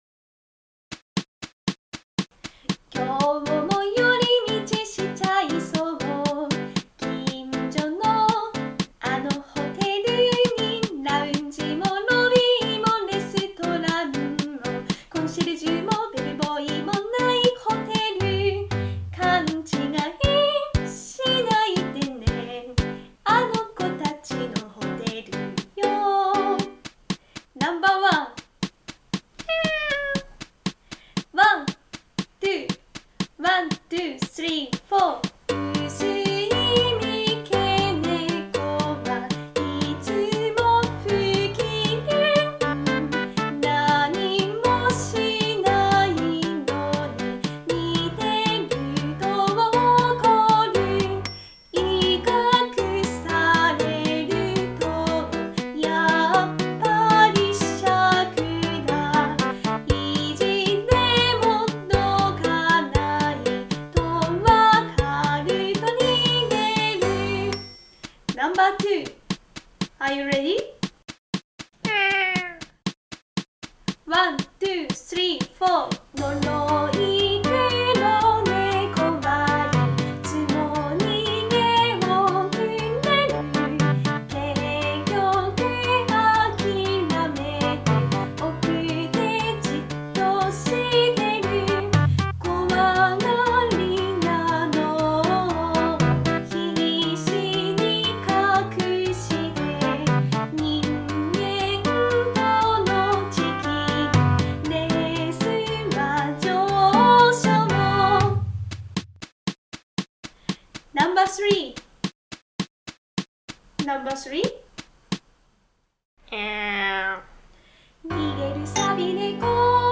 ※自作のJingleを入れました。
※この楽曲の参考演奏は「低声用」です。